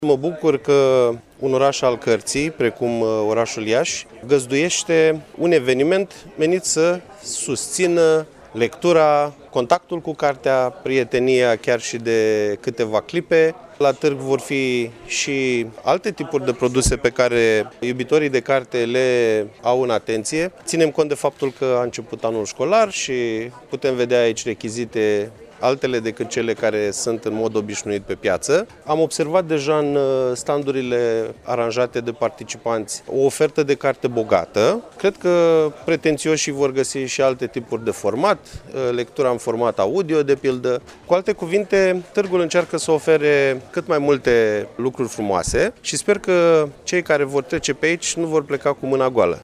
Peste 40 de edituri din țară sunt prezente, începând de astăzi, la Iași, la cea de-a treia ediție a Târgului de Carte „Gaudeamus – Radio România”.